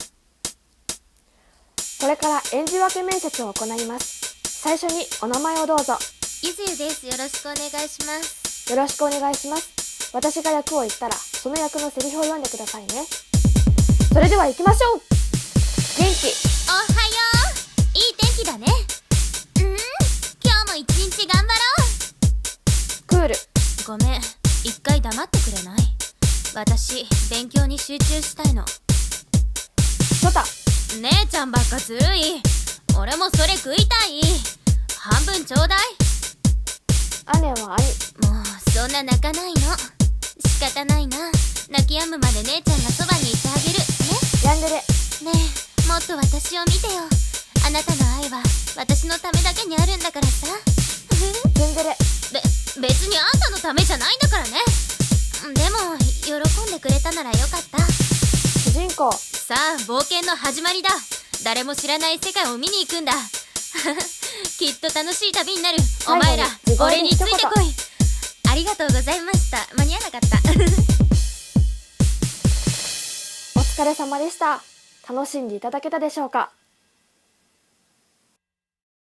7役演じ分け声面接!!